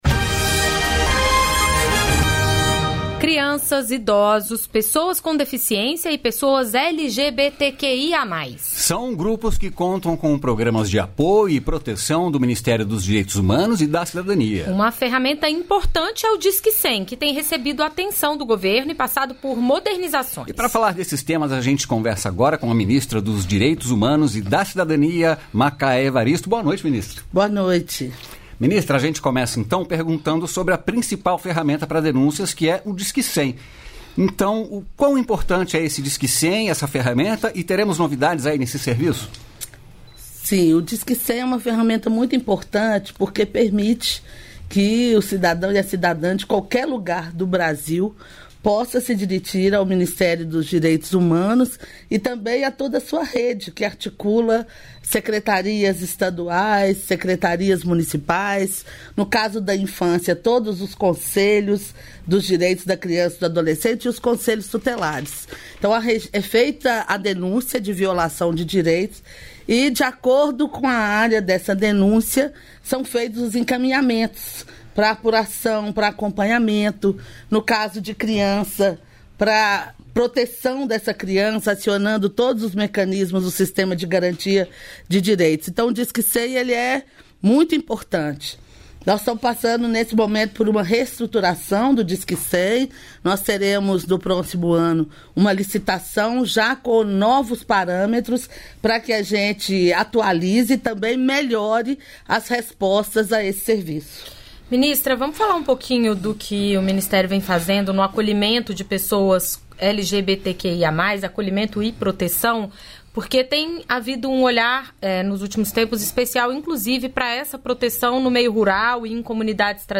Giuseppe Vieira, secretário Nacional de Segurança Hídrica, do MIDR
O Projeto de Integração do Rio São Francisco e o investimento em obras de bombeamento é prioridade no Novo PAC. Sobre essas melhorias, a Voz do Brasil entrevistou Giuseppe Vieira, secretário nacional de Segurança Hídrica do Ministério da Integração e do Desenvolvimento Regional.